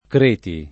Creti [ kr % ti ]